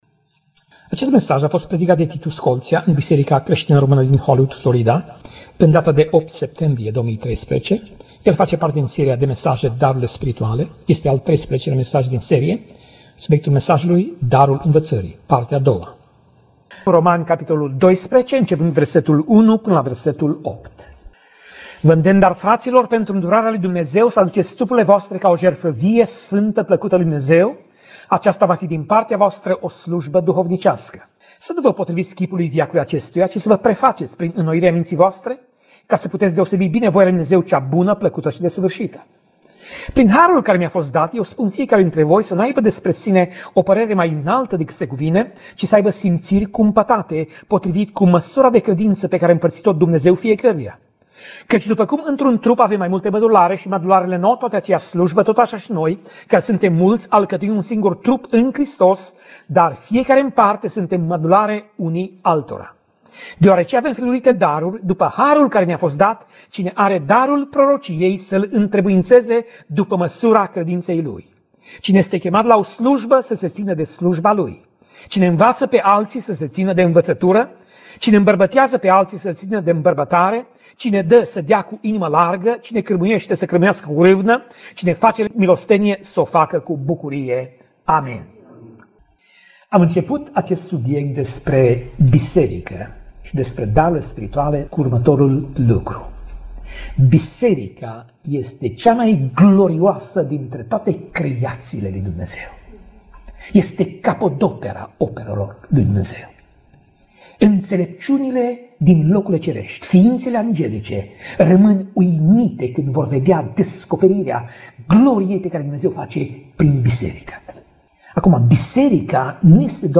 Pasaj Biblie: Romani 12:1 - Romani 12:8 Tip Mesaj: Predica